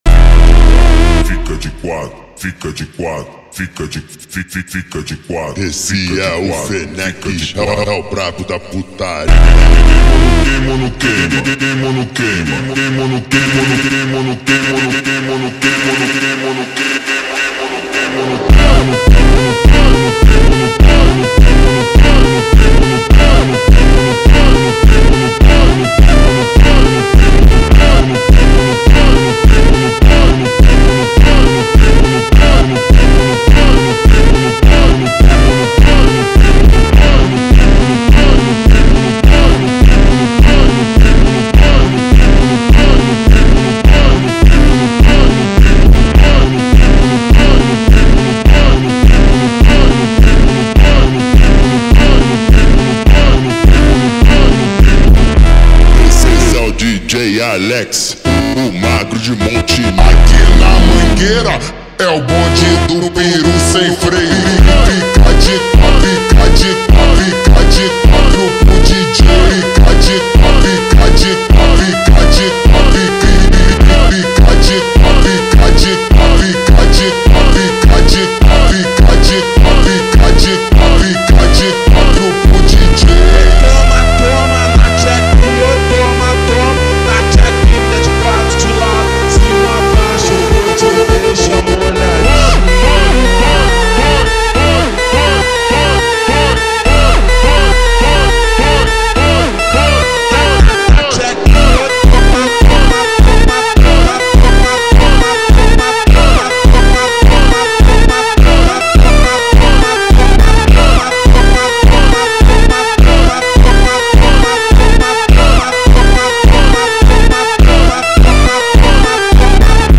فانک کند شده
فانک